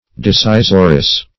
Disseizoress \Dis*sei"zor*ess\, n.